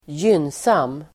Uttal: [²j'yn:sam:]